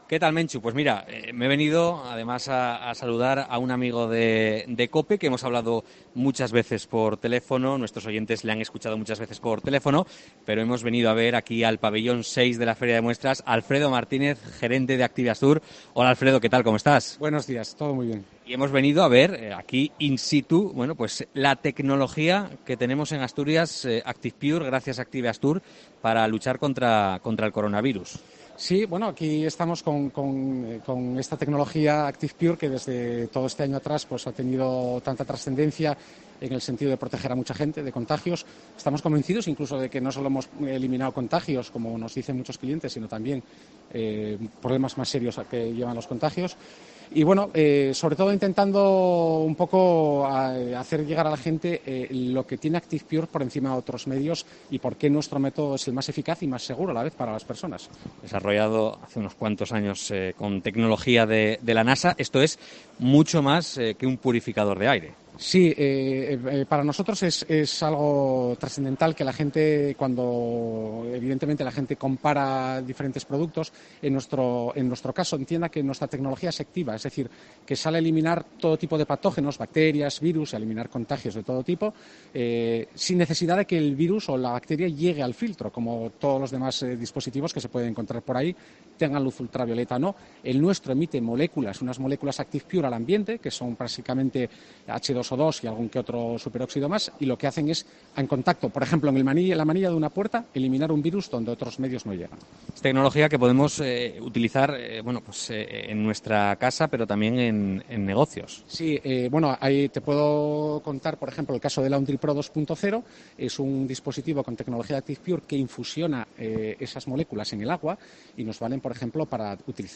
COPE EN LA FIDMA
Entrevista